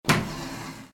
kick-block.ogg